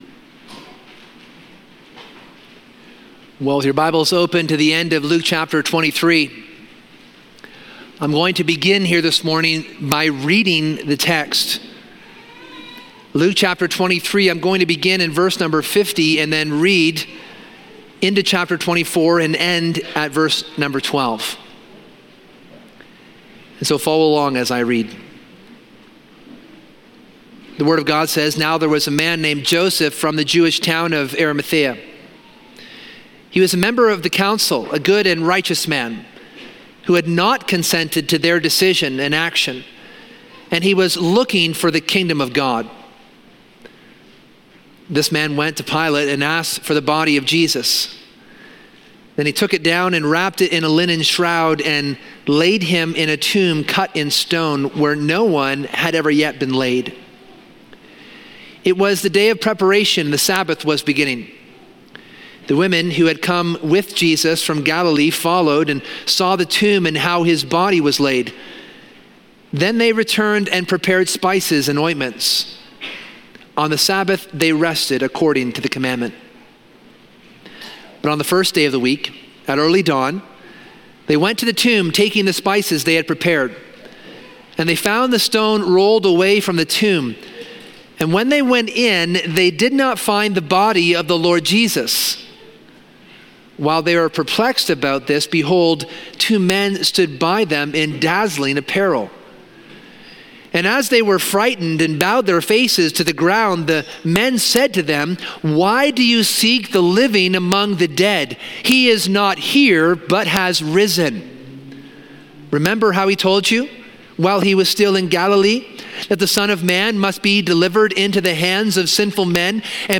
This sermon explores the historical reality of Jesus’ resurrection, using inference to the best explanation to evaluate evidence such as his death, burial, empty tomb, and eyewitness accounts.